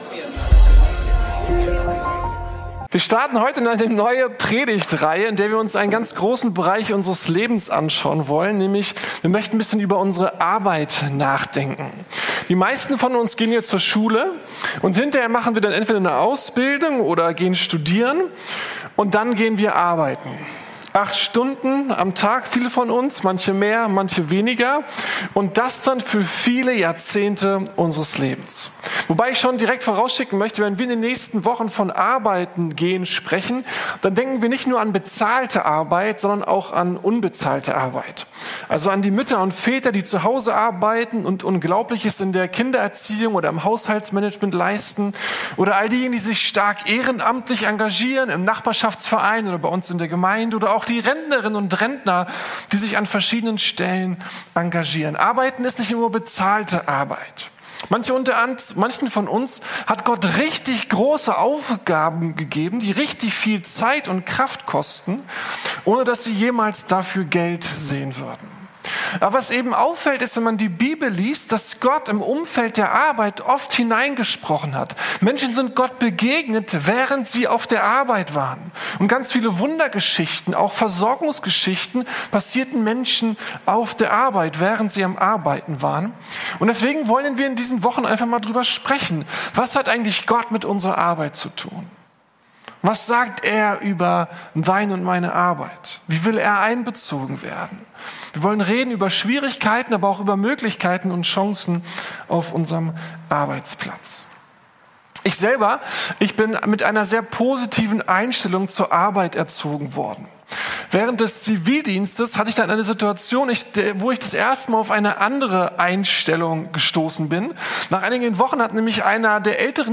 Glaube und Arbeit: Unsere Haltung zur Arbeit ~ Predigten der LUKAS GEMEINDE Podcast